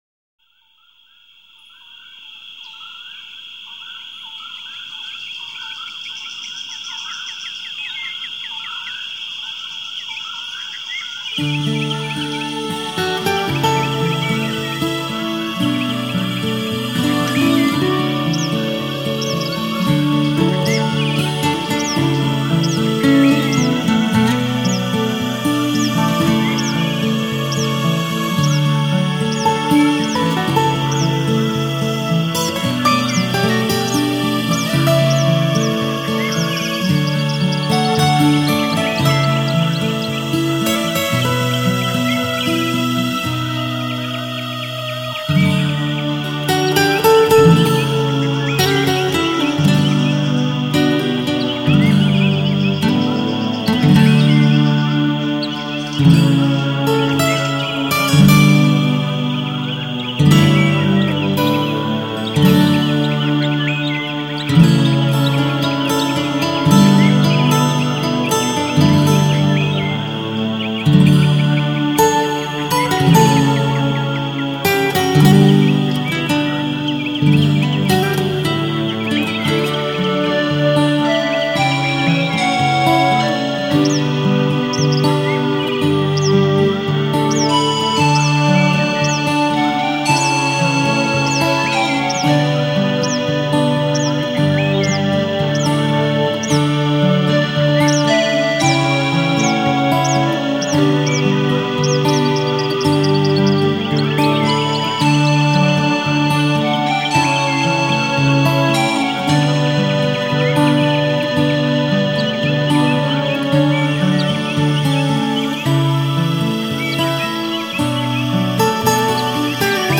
音乐类型：新世纪音乐(NEW AGE)